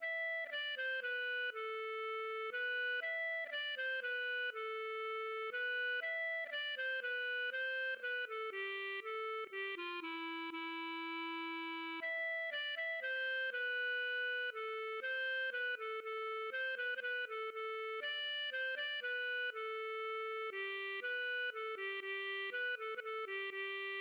Ein Minnelied, gefunden auf Burg Luringen, Autor unbekannt: